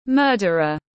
Kẻ giết người có chủ đích tiếng anh gọi là murderer, phiên âm tiếng anh đọc là /ˈmɜː.dər.ər/.